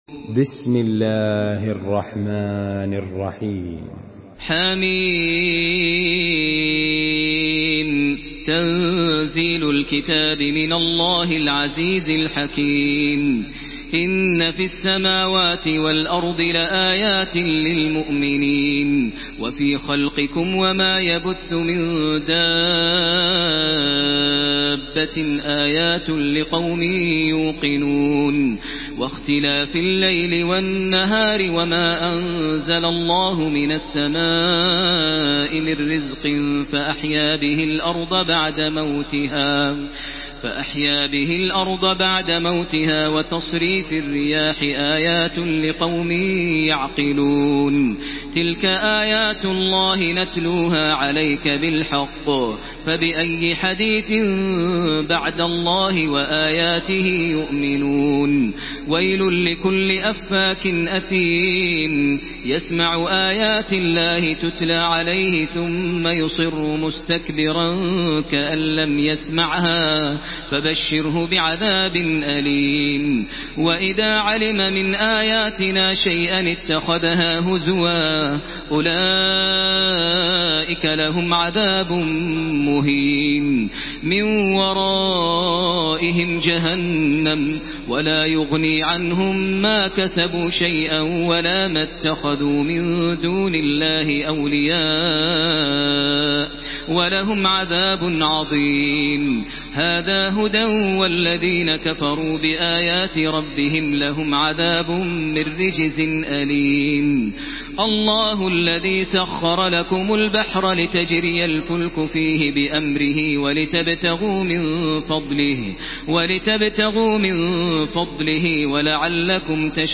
Surah Al-Jathiyah MP3 in the Voice of Makkah Taraweeh 1433 in Hafs Narration
Murattal Hafs An Asim